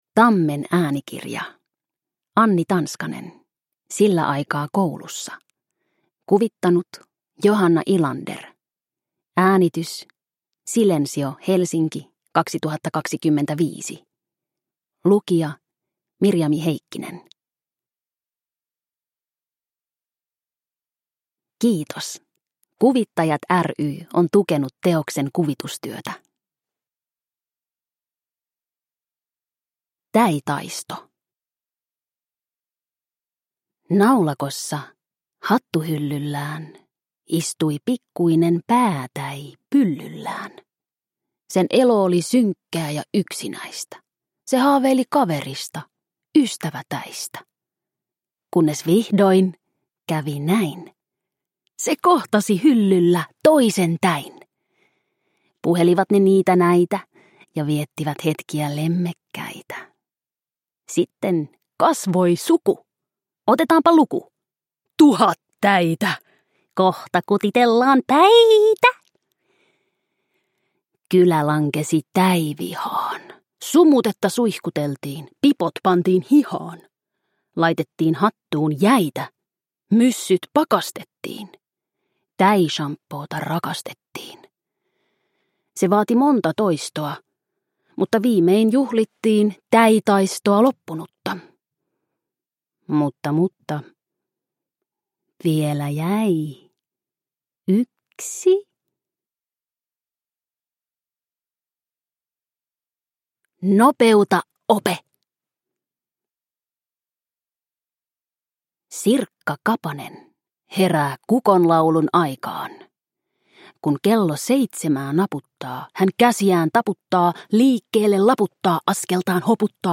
Sillä aikaa koulussa – Ljudbok